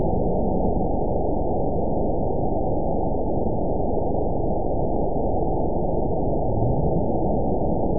event 921976 date 12/24/24 time 00:24:06 GMT (4 months, 2 weeks ago) score 9.38 location TSS-AB02 detected by nrw target species NRW annotations +NRW Spectrogram: Frequency (kHz) vs. Time (s) audio not available .wav